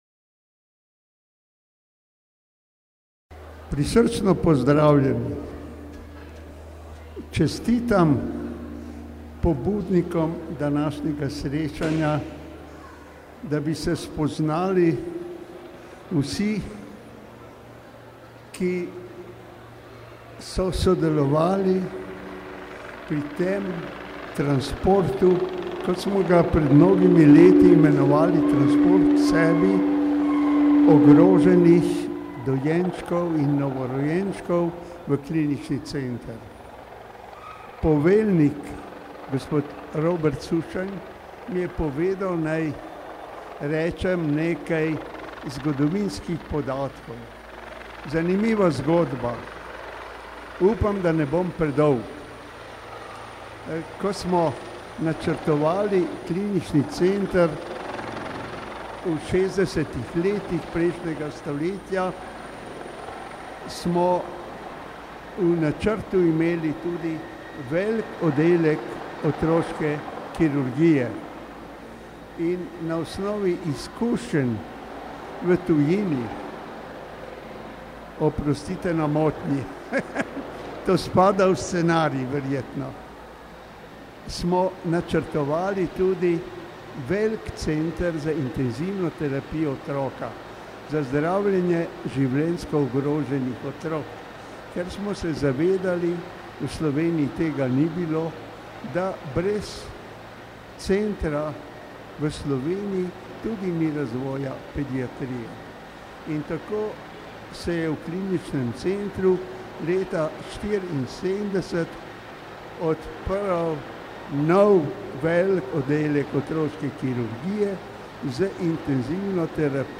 V hangarju Letalske policijske enote je bilo več kot dovolj prostora za dobro voljo in veselje.
V svojem govoru je izrazil veliko veselje, da pred sabo vidi toliko zdravih otrok in njihovih staršev.